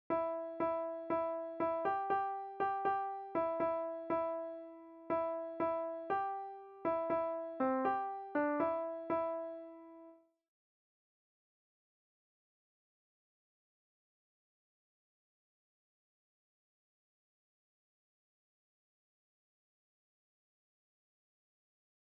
Contralti